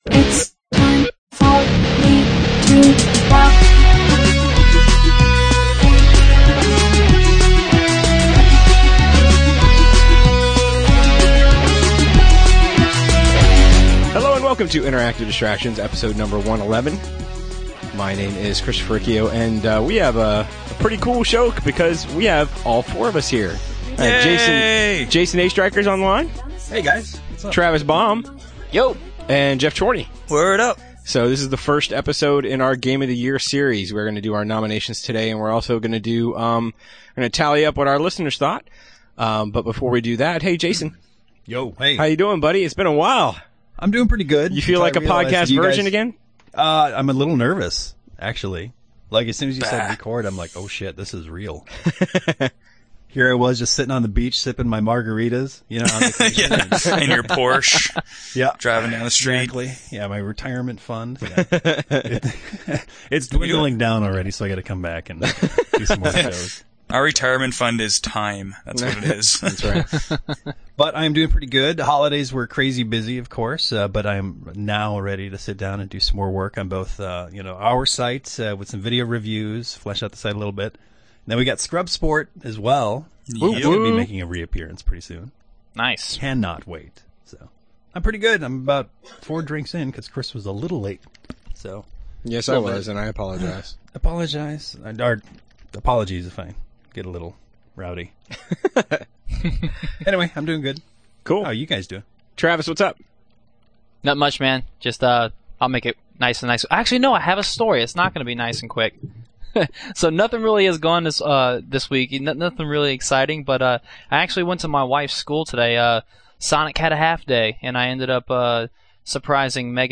All 4 members this week.